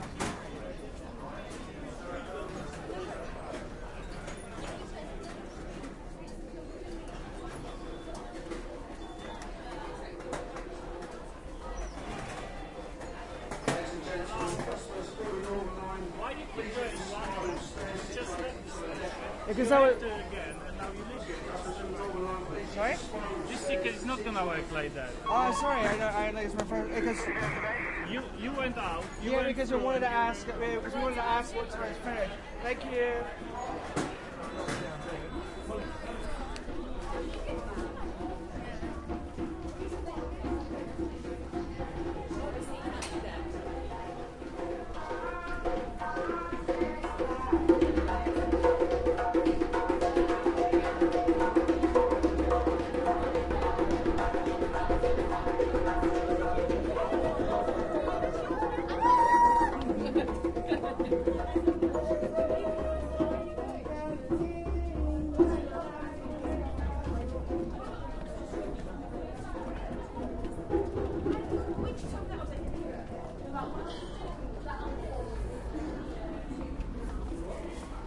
描述：巴士司机非洲鼓
标签： 非洲 街头艺人
声道立体声